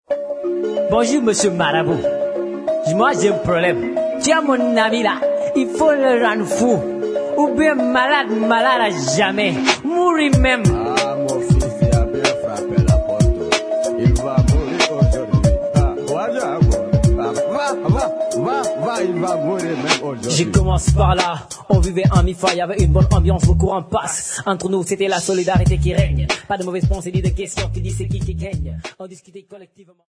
field recordings
Hip hop song sung in English, French and Wolof
Cassette tape